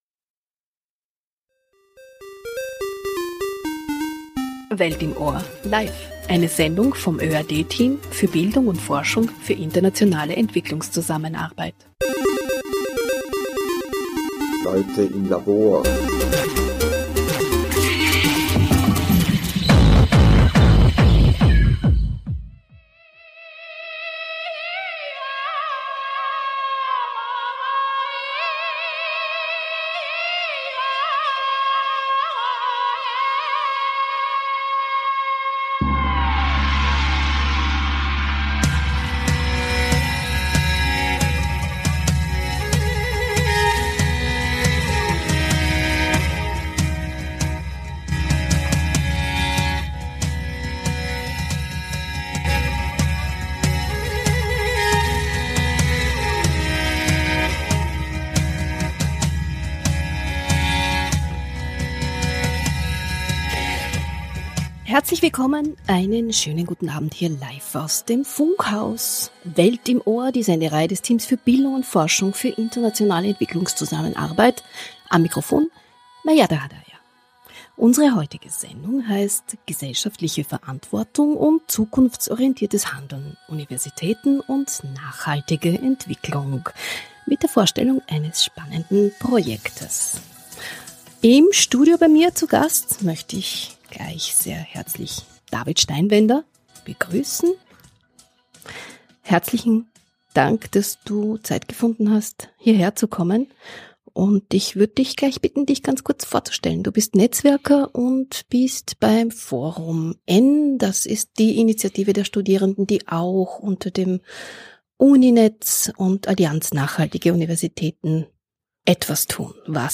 Bei der ersten UniNEtZ Veranstaltung diskutierten Wissenschaftler/innen und Forscher/innen über den Beitrag ihrer Universitäten und Hochschulen für nachhaltige Entwicklung im Rahmen der SDGs.